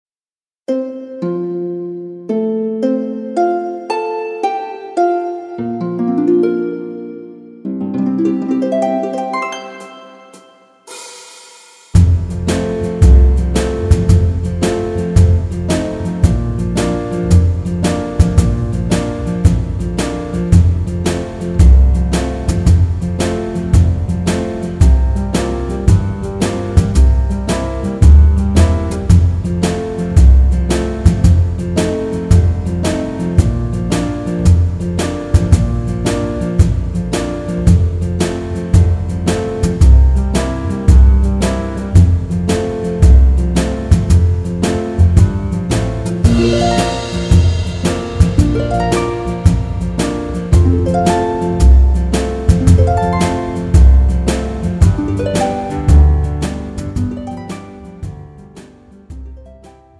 고음질 반주
축가, 웨딩, 결혼식 MR. 원하는 MR 즉시 다운로드 가능.